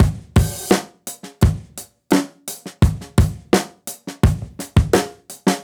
Index of /musicradar/dusty-funk-samples/Beats/85bpm
DF_BeatD_85-02.wav